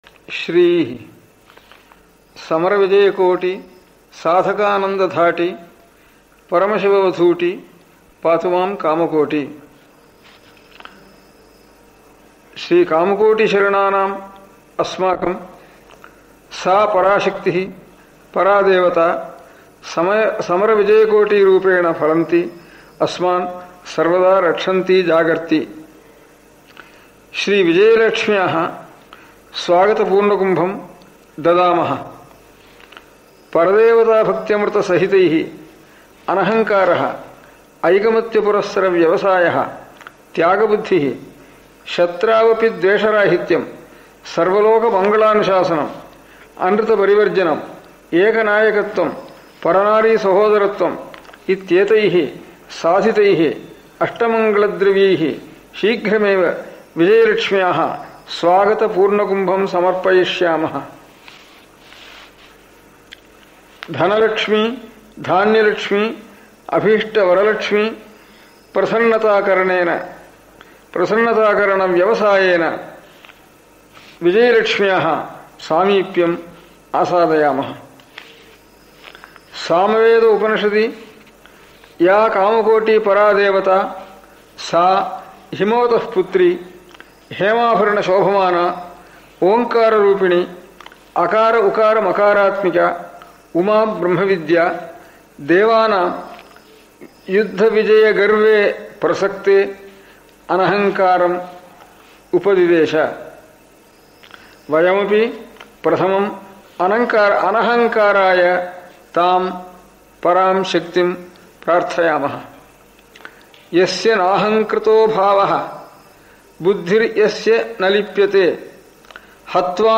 On this Occasion I share with you the GOLDEN VOICE OF SRI PARAMACHARYA in TAMIL and SANSKRIT, the message He gave to the Nation during Chinese Aggression on India (1962).
Paramacharya’s Message in Sanskrit in His own Voice:
Message-in-Sanskrit-by-His-Holiness-Sankaracharya-of-Kanchi-Kamakoti-Peetam-during-external-invasion.mp3